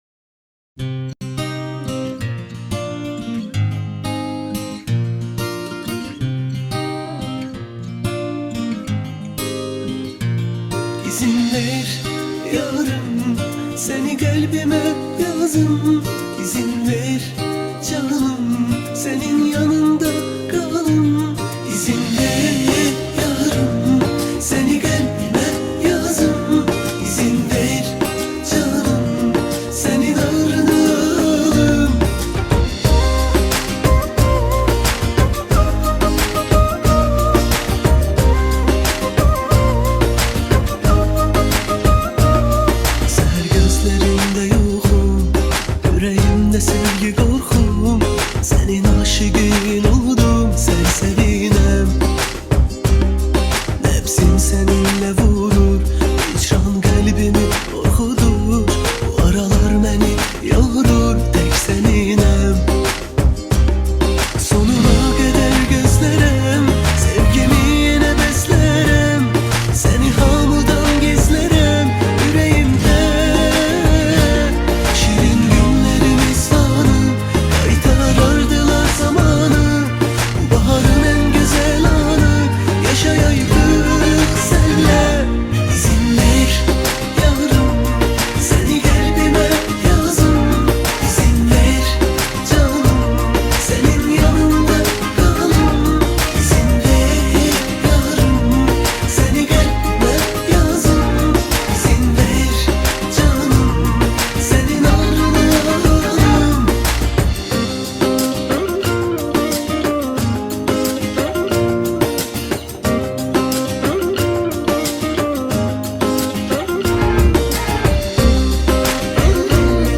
آهنگ آذربایجانی آهنگ شاد آذربایجانی آهنگ هیت آذربایجانی